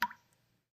feat: water sounds instead of bells
splash-tiny.mp3